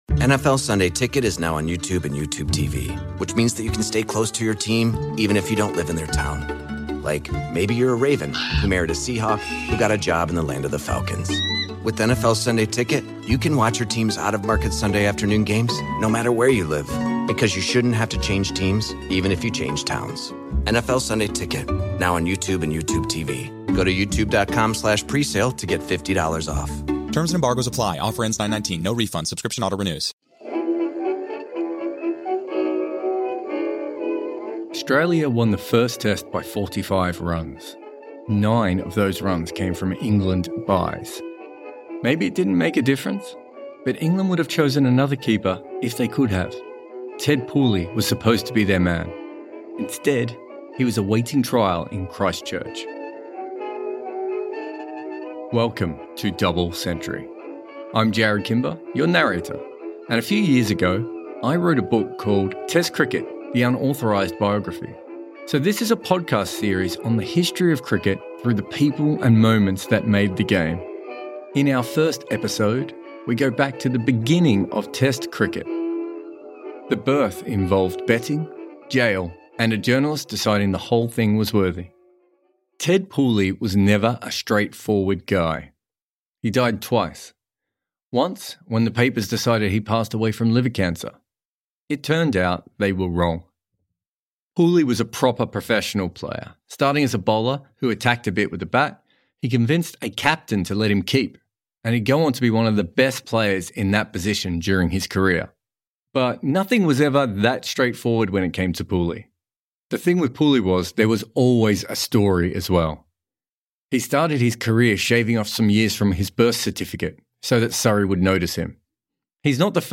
wrote and narrated this episode